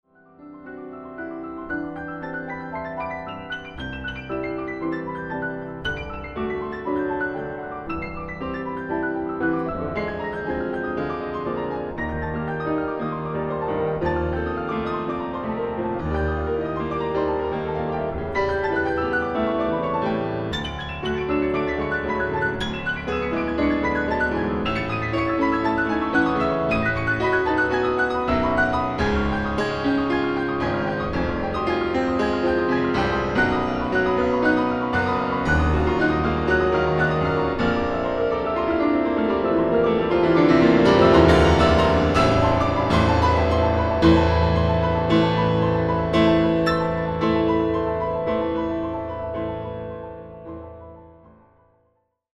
dramatic solo piano work